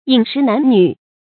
飲食男女 注音： ㄧㄣˇ ㄕㄧˊ ㄣㄢˊ ㄋㄩˇ 讀音讀法： 意思解釋： 泛指人的本性。